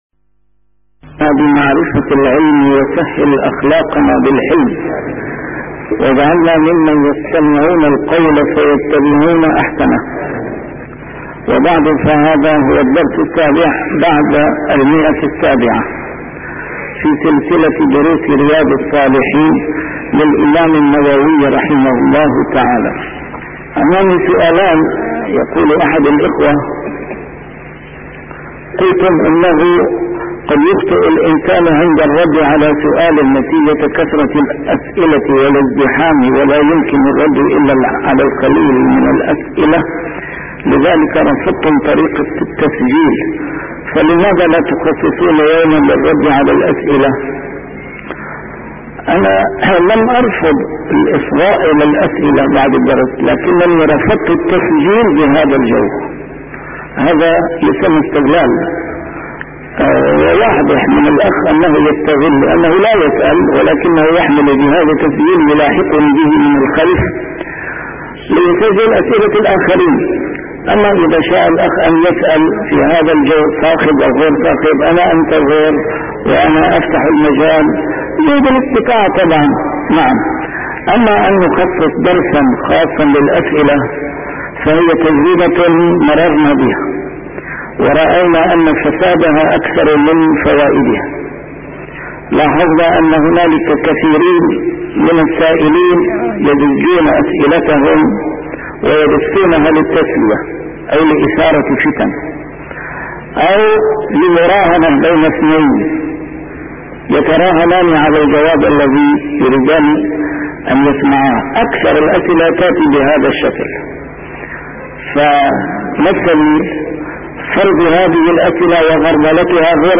A MARTYR SCHOLAR: IMAM MUHAMMAD SAEED RAMADAN AL-BOUTI - الدروس العلمية - شرح كتاب رياض الصالحين - 707- شرح رياض الصالحين: شكوى المريض